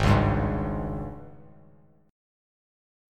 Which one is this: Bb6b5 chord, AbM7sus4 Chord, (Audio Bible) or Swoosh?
AbM7sus4 Chord